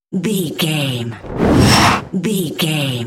Whoosh fast jet bright
Sound Effects
futuristic
intense
whoosh